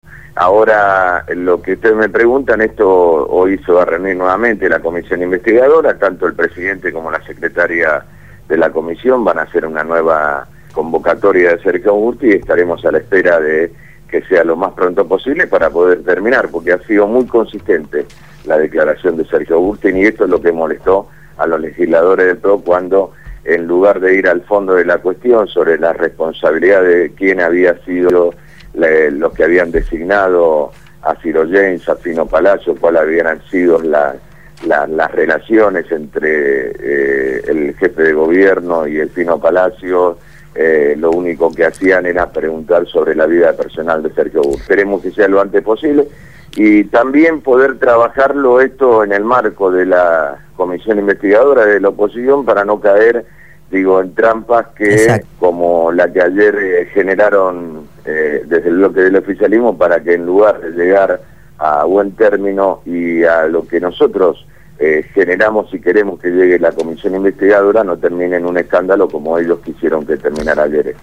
entrevistaron al Legislador del EPV Francisco «Tito» Nenna